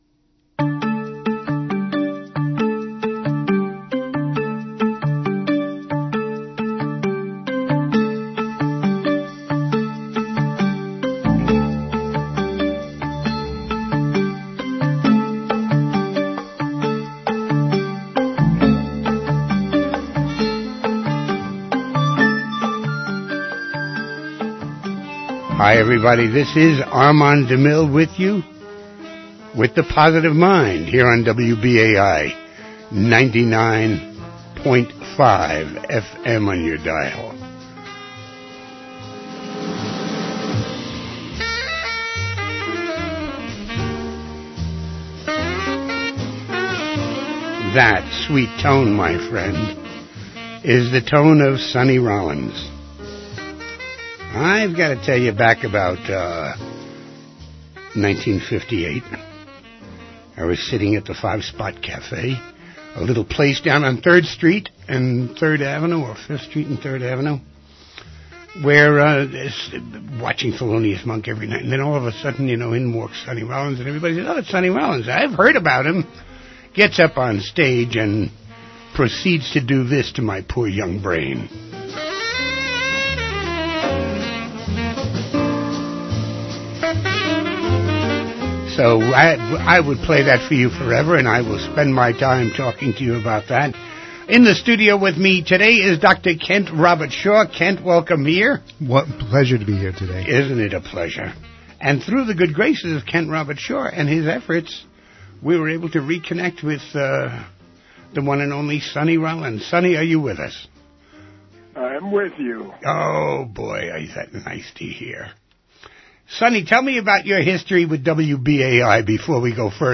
Interview with Sonny Rollins - Jazz Legend